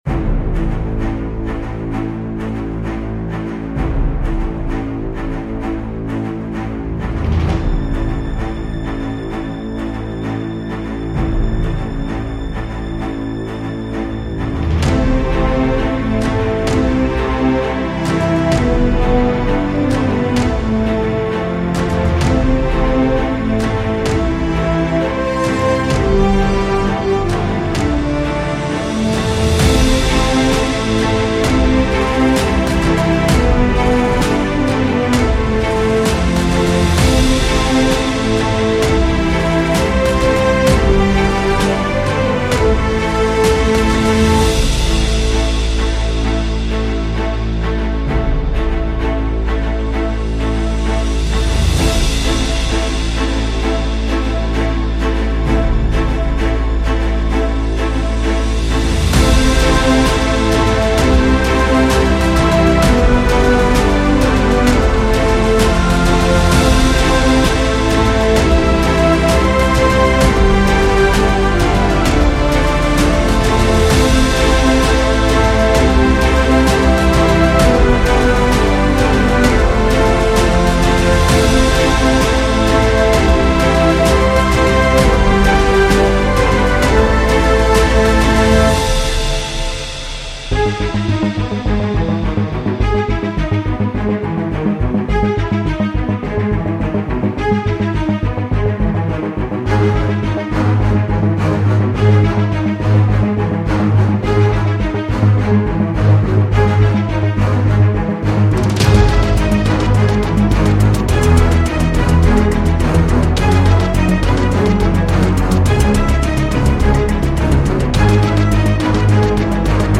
Cinematic Construction Kits包。
集合了惊人的Cinematic元素，例如音调，碰撞，冲击，弦，低音提琴，合唱团，铜管，合成器Arp和
-5个混音演示
– 105-160 BPM